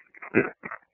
This is the room where we recorded the evp's. This room is almost directly accross from room 410, the haunted Mary Lake Room.
EVP's